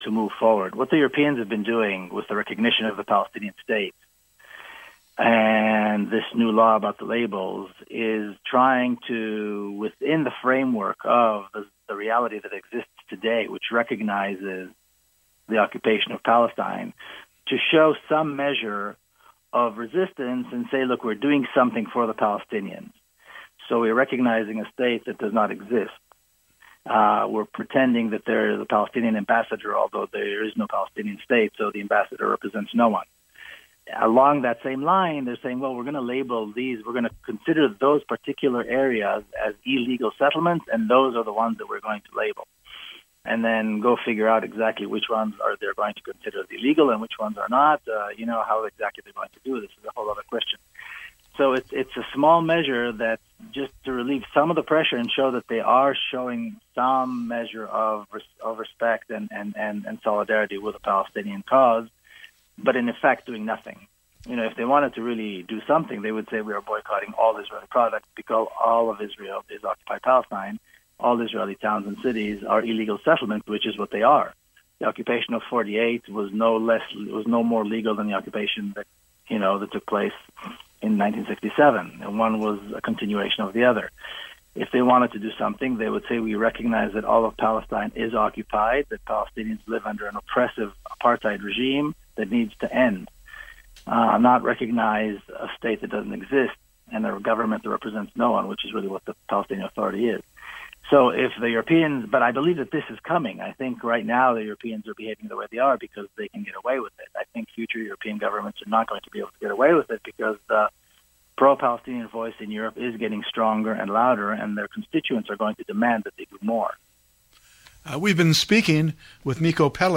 Despite what he knows, Tim Flannery explains new "natural" based tech that may prevent climate catastrophe. Tim Flannery speech recorded at the Town Hall in Seattle November 12, 2015 by ...